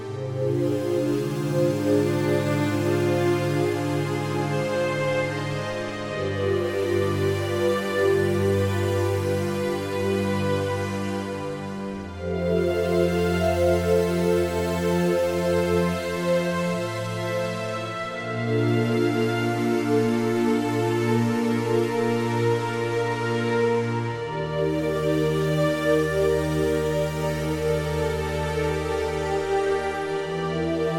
musique d'ambiance : relaxation
Musique Fonctionnelle